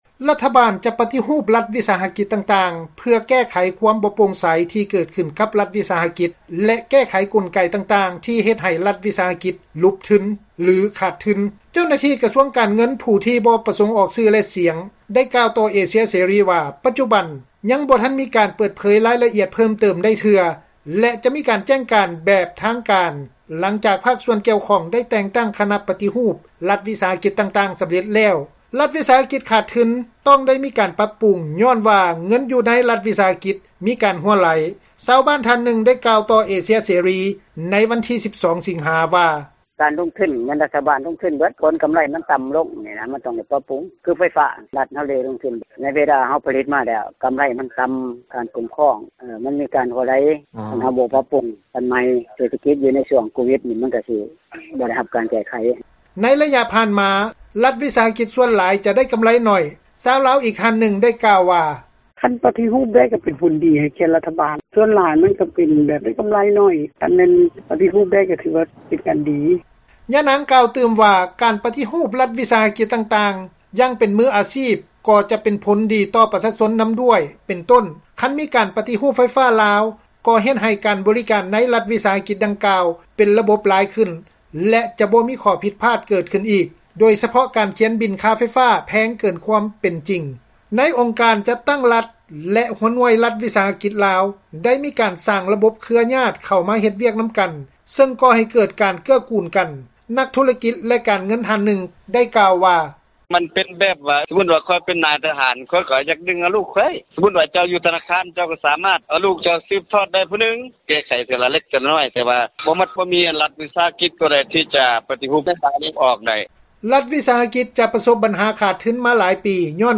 ໃນອົງການຈັດຕັ້ງຣັຖ ແລະ ຫົວໜ່ວຍຣັຖວິສາຫະກິຈລາວ ໄດ້ມີການສ້າງລະບົບເຄືອຍາດ ເຂົ້າມາເຮັດວຽກນຳກັນ ຊຶ່ງກໍ່ໃຫ້ເກີດ ການເກື້ອກູນກັນ. ນັກທຸຣະກິຈ ແລະການເງິນ ທ່ານນຶ່ງ ໄດ້ກ່າວວ່າ:
ທ່ານ ບຸນໂຈມ ອຸບົນປະເສີດ ຣັຖມົນຕຣີ ກະຊວງການເງິນ ໄດ້ກ່າວຢູ່ໃນ ກອງປະຊຸມສໄມສາມັນ ເທື່ອທີ 1 ຂອງ ສະພາແຫ່ງຊາດ ຊຸດທີ 9 ໃນວັນທີ 6 ສິງຫາ 2021 ຕອນນຶ່ງວ່າ: